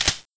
Index of /server/sound/weapons/tfa_cso/p228
sliderelease1.wav